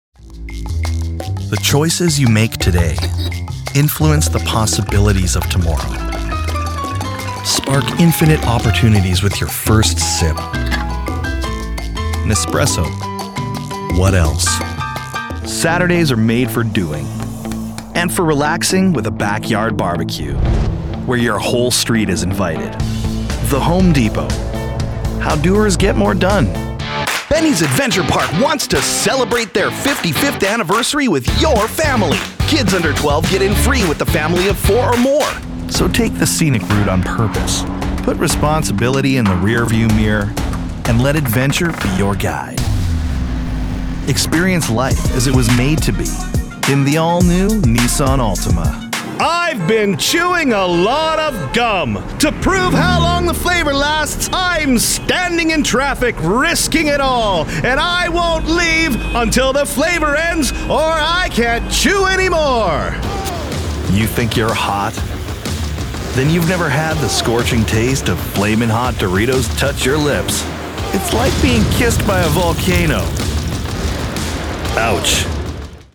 A Robust Baritone Full of Character
Commercial Comp - English
English - General, English - US, English - Canadian/US Midwest, English - US South